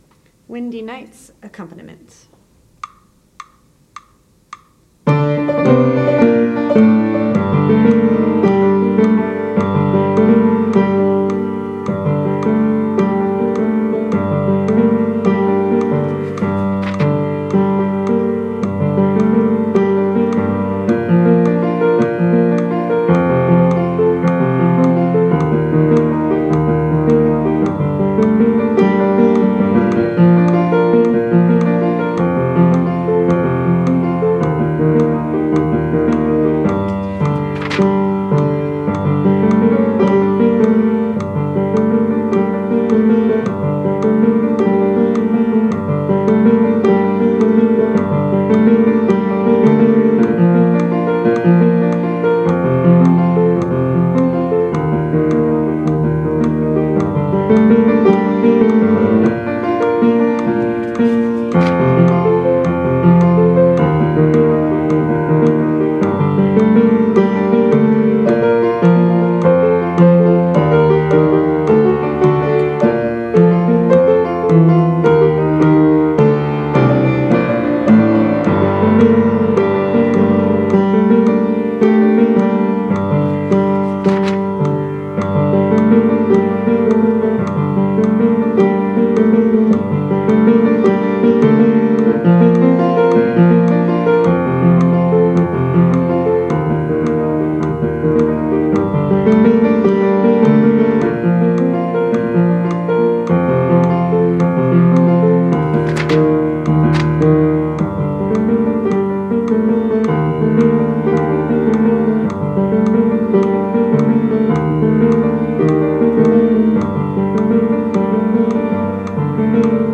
WindyNightsPiano.mp3